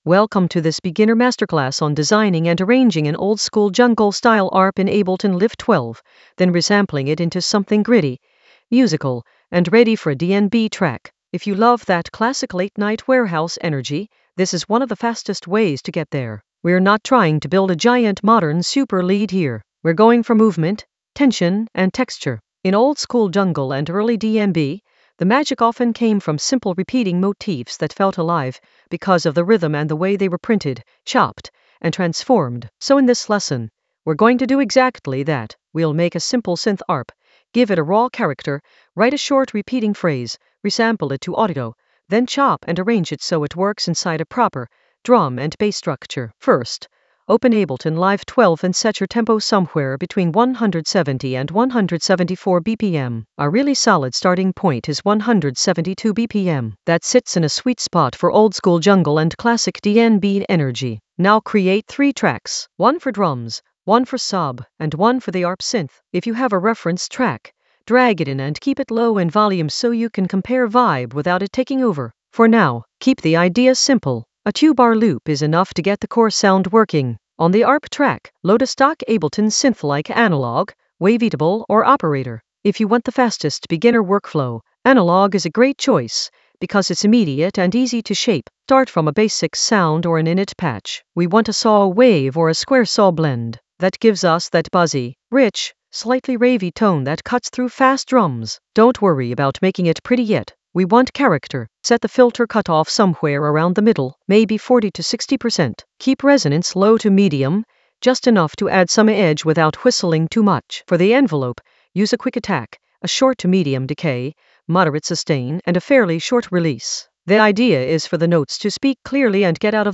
An AI-generated beginner Ableton lesson focused on Oldskool masterclass oldskool DnB jungle arp: design and arrange in Ableton Live 12 in the Resampling area of drum and bass production.
Narrated lesson audio
The voice track includes the tutorial plus extra teacher commentary.